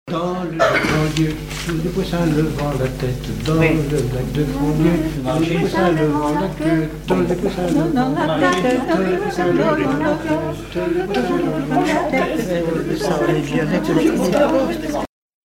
Mémoires et Patrimoines vivants - RaddO est une base de données d'archives iconographiques et sonores.
Couplets à danser
branle : courante, maraîchine
Pièce musicale inédite